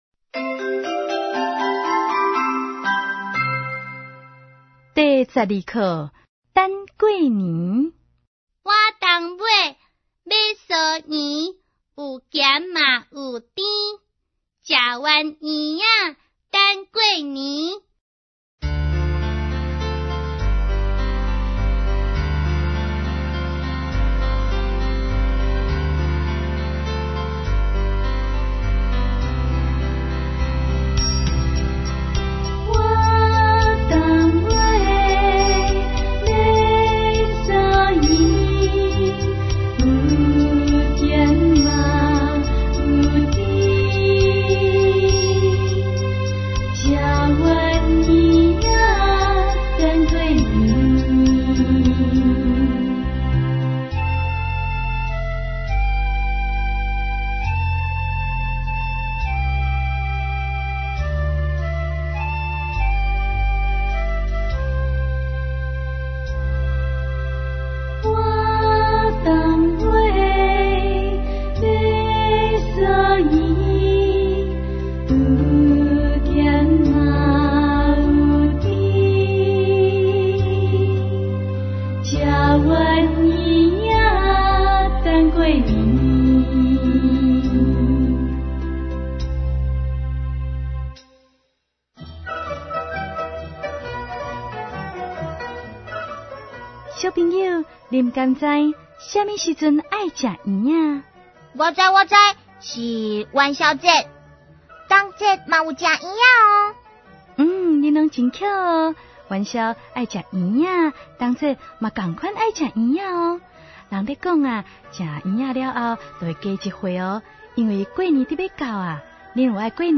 ● 兒歌唱遊、常用語詞、短句對話 ●